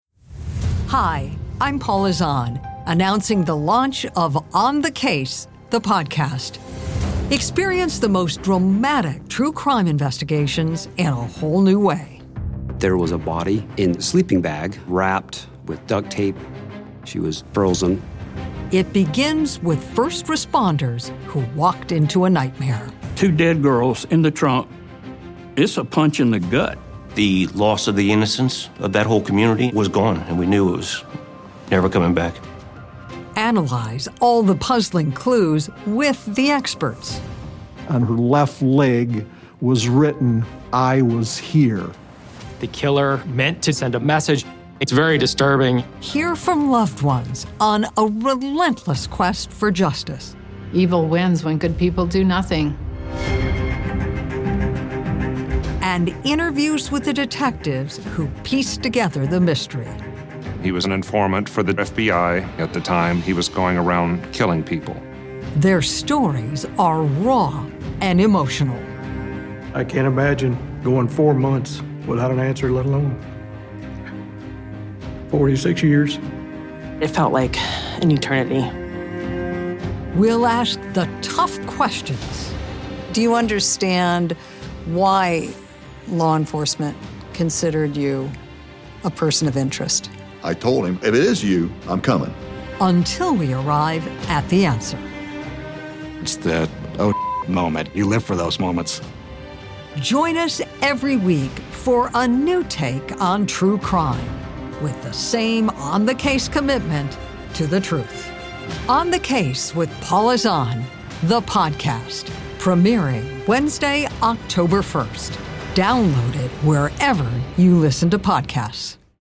The stories are woven together by Paula’s in-depth interviews, that take listeners through all the steps that led to solving a complex murder, including all its emotional twists and turns.